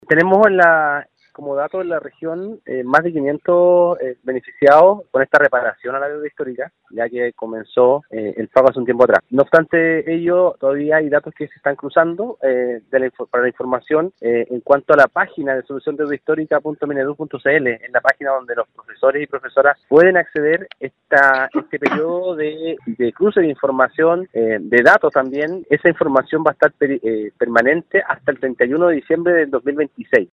Al respecto, el seremi de Educación en Los Lagos, Juan Eduardo Gómez, señaló que ya hay un grupo de personas que resultaron beneficiadas, mientras que efectivamente aún restan docentes que están a la espera de recibir este pago.